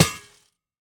Minecraft Version Minecraft Version latest Latest Release | Latest Snapshot latest / assets / minecraft / sounds / block / spawner / break4.ogg Compare With Compare With Latest Release | Latest Snapshot